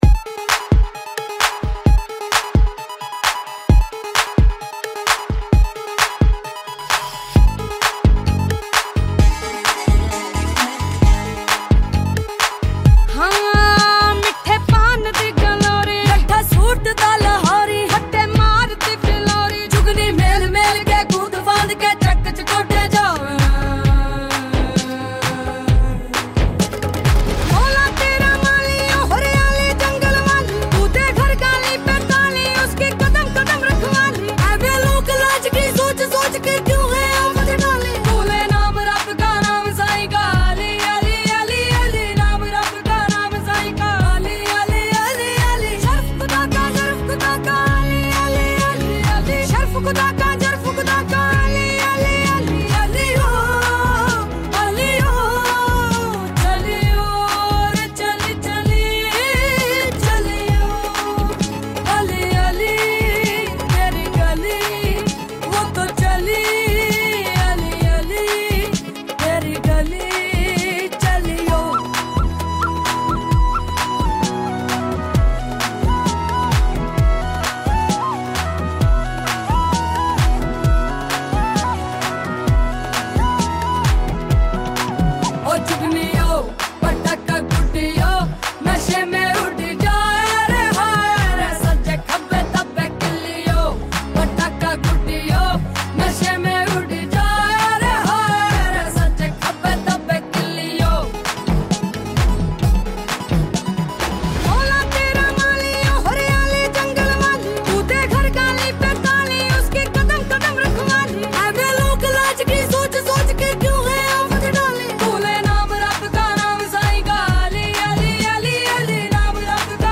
BPM131
Audio QualityCut From Video